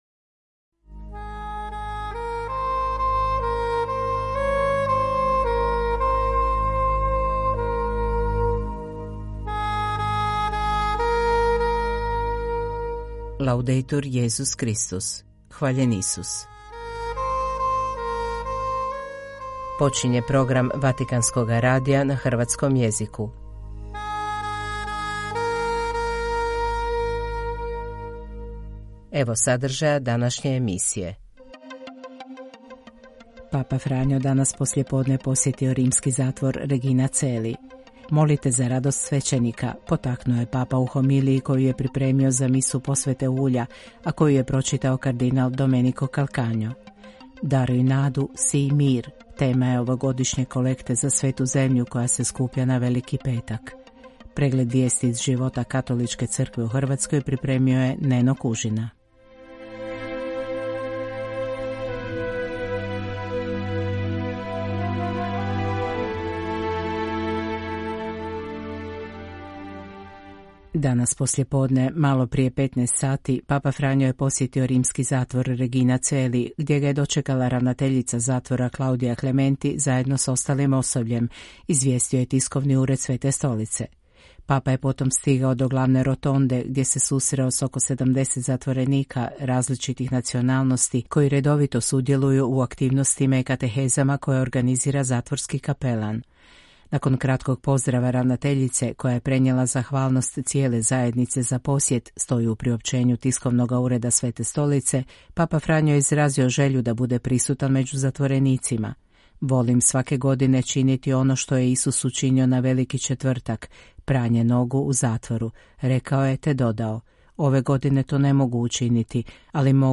Vatican News - Hrvatski Program 214 subscribers updated 21h ago Подписаться Подписка добавлена Прослушать Воспроизведение Поделиться Отметить все как (не)прослушанные ...